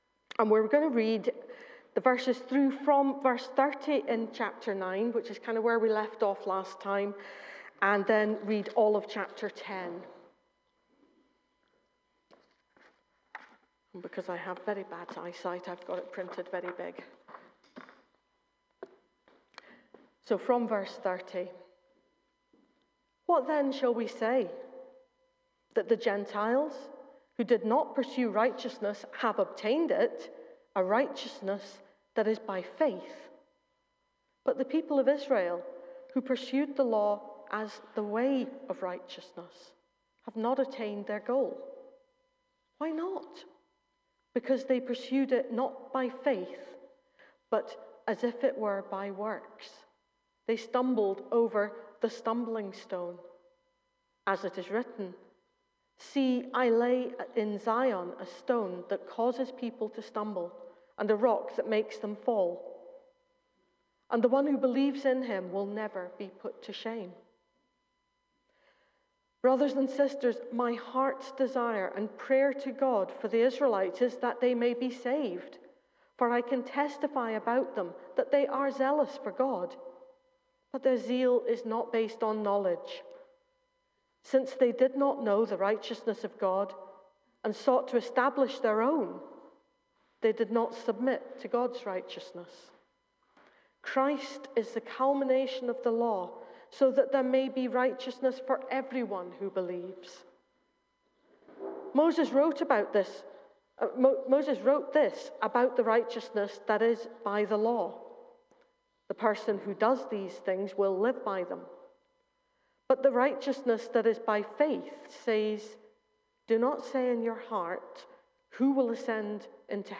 Bridge of Don Baptist Church Sermons
The weekly sermons from Bridge of Don Baptist Church, Aberdeen are available to listen here for free.